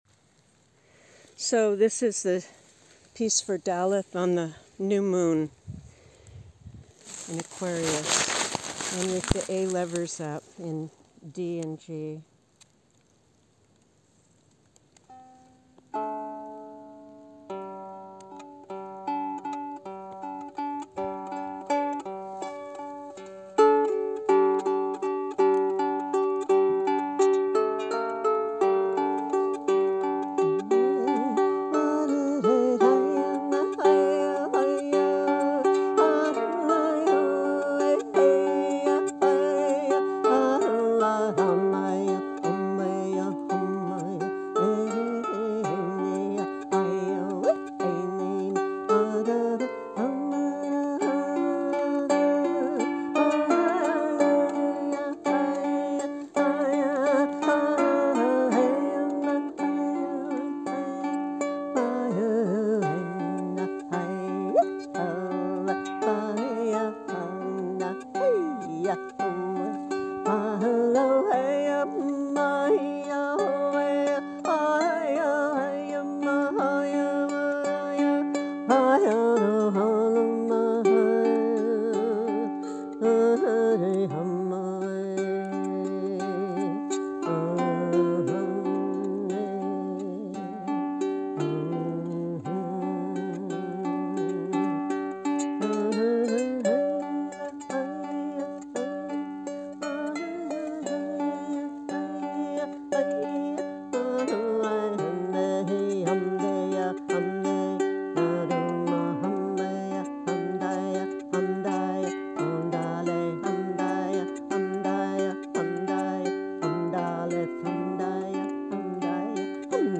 Here is a field recording of Serenading Stones, a spontaneous song at a menhir near Bouriege, France on Imbolc, 2025: